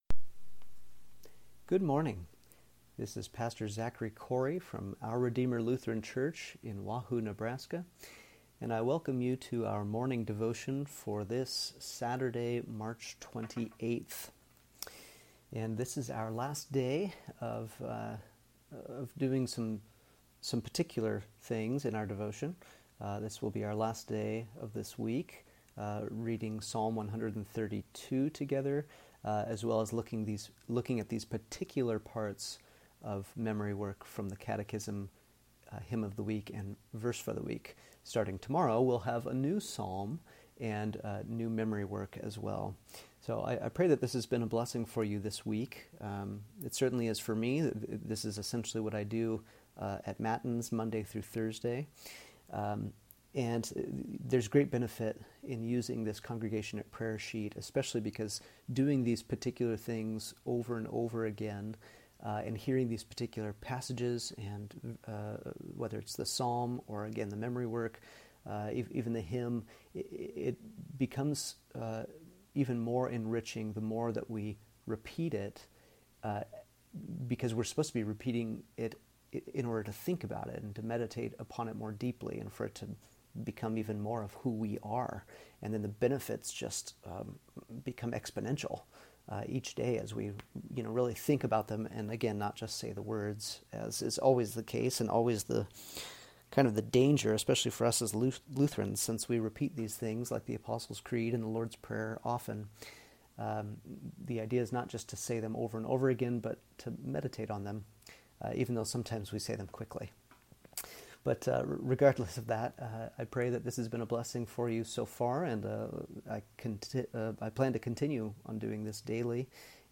Morning Devotion for Saturday, March 28th